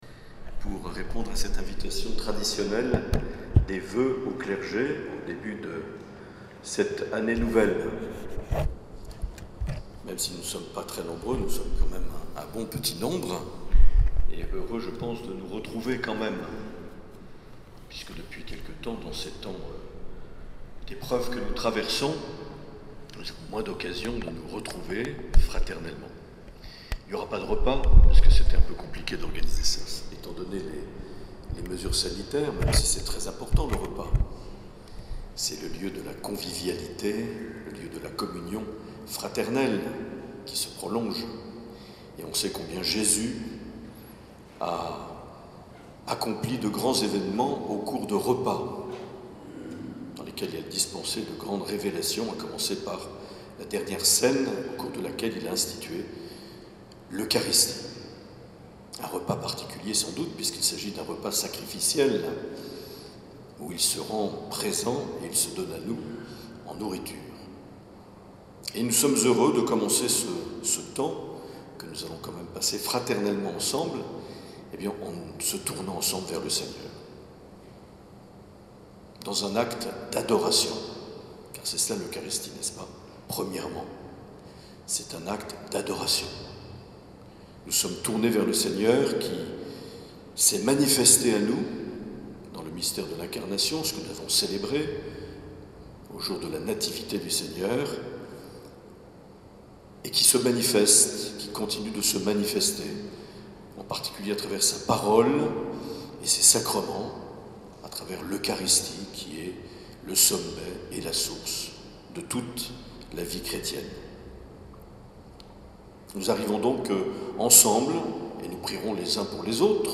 7 janvier 2021 - Salies-de-Béarn - Voeux au clergé